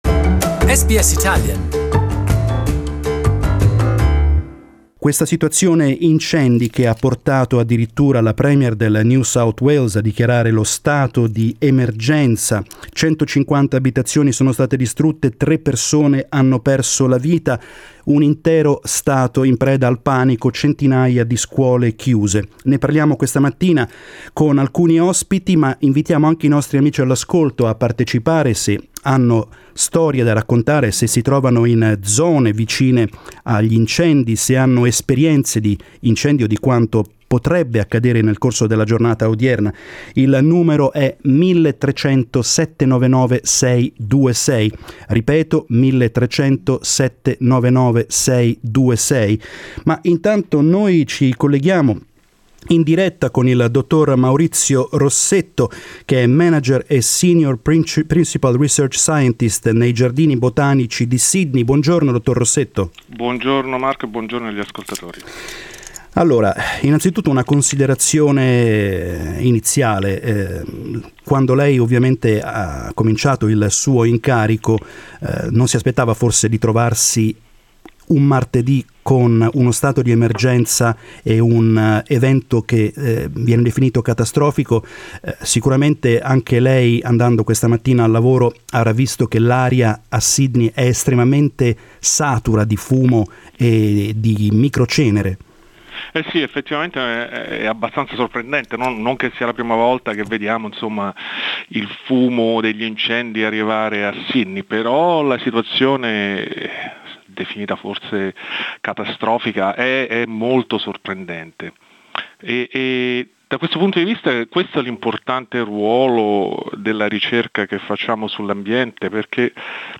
As a state of emergency is in place for New South Wales, we talk to a scientist and an affected resident.